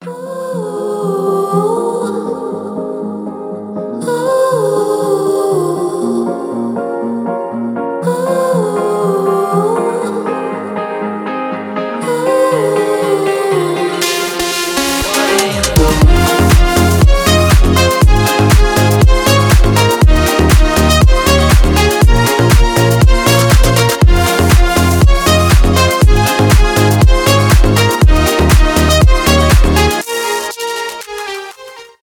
танцевальные
deep house
нарастающие , красивые